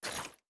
goodcircle/IdleRPG2024 - Assets/_8Sound/Grenade Sound FX/Different sounds/Throw3.wav at cc647107d762824fa3c32648ad9142fc232b2797 - IdleRPG2024 - GoodCircle
Throw3.wav